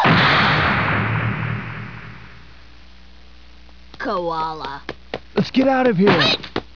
Lina hits a tree and utters the infamous line, "Koala".(6.76 sec, 74K)